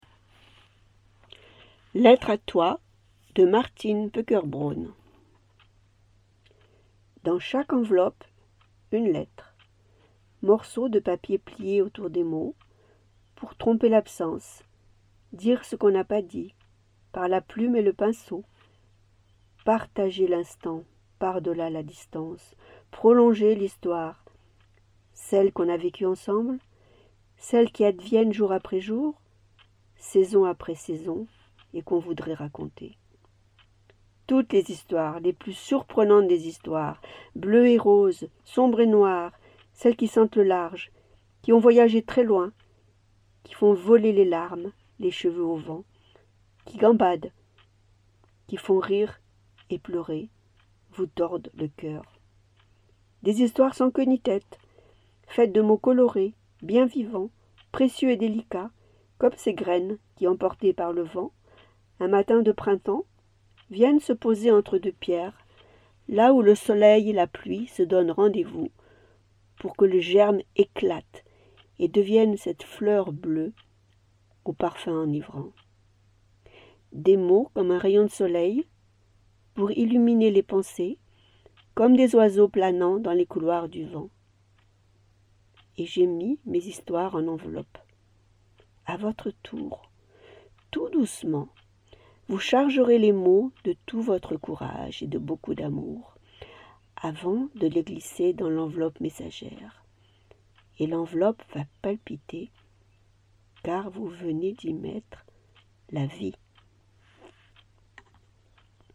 Ecoutez la présentation de « Lettres à toi »